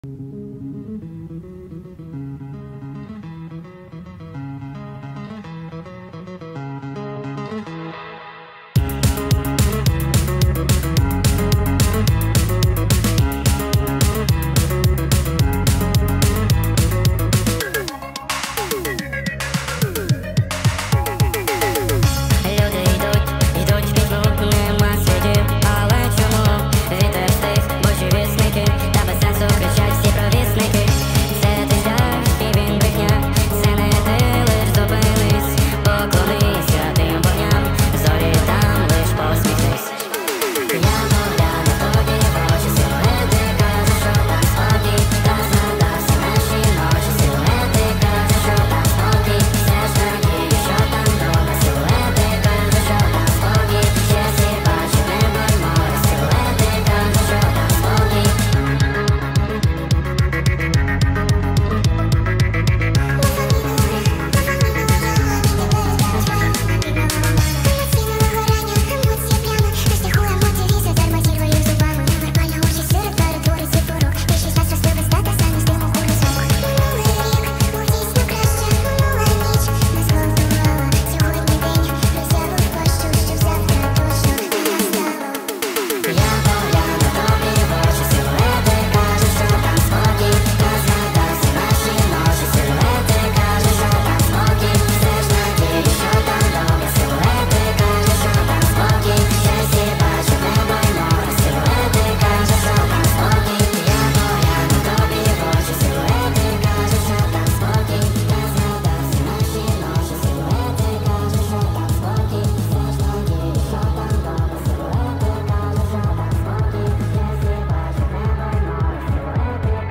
TikTok remix